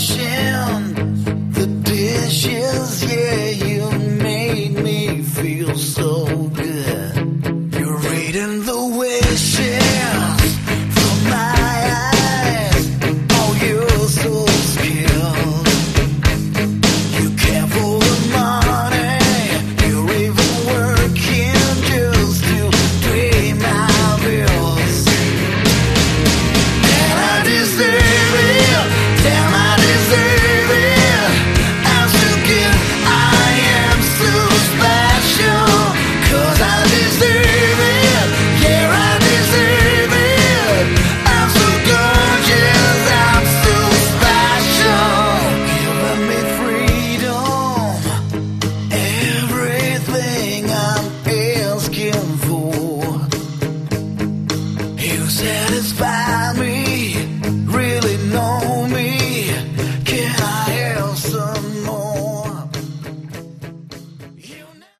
Category: Melodic Hard Rock
Vocals, Bass, Guitar, Keyboards
Drums, Backing Vocals